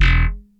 E1 3 F.BASS.wav